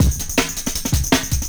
Hot Pantz 160bpm.wav